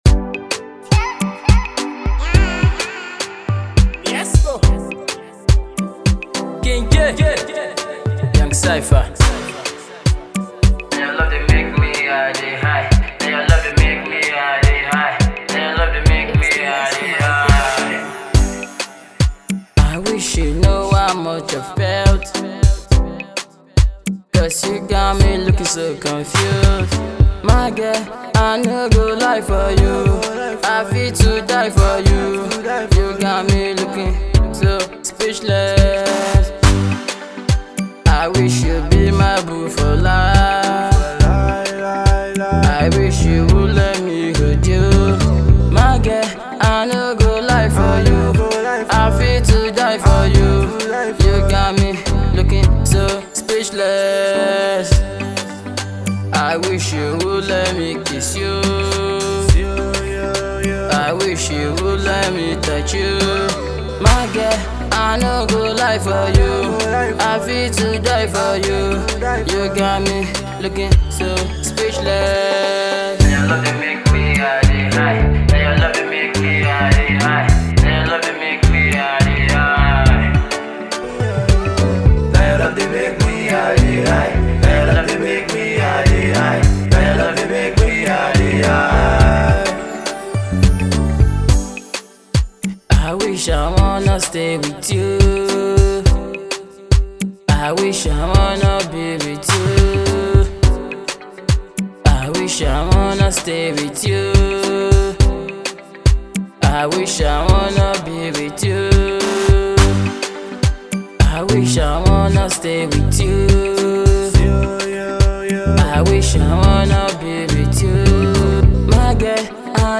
emotional love song